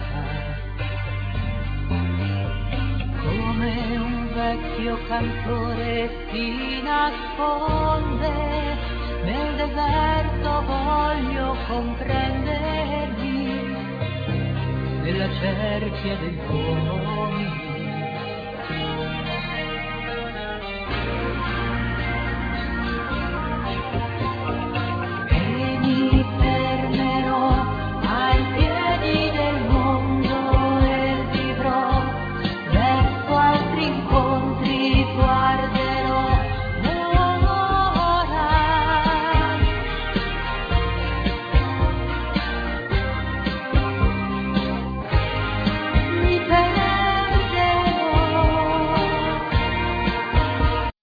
Vocal
Bass
Trumpet
Guitars